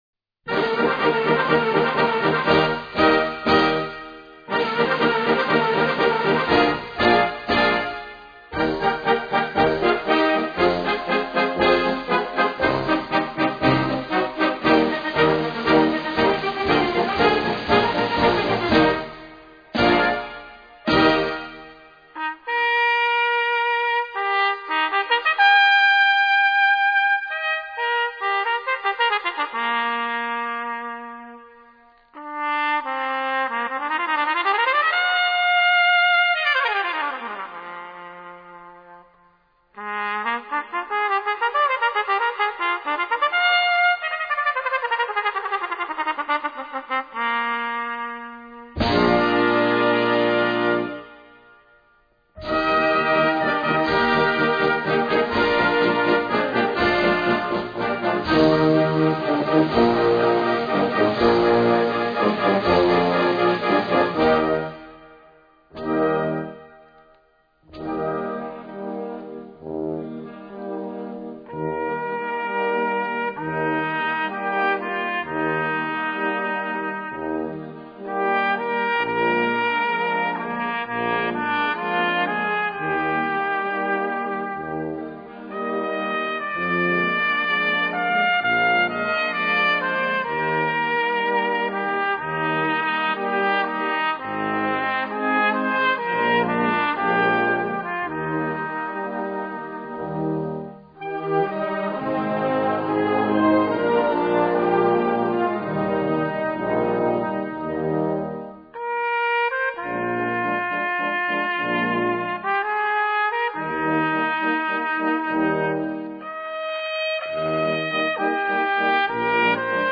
Bravour-Polka für Solo-Trompete und Blasorchester
Besetzung: Blasorchester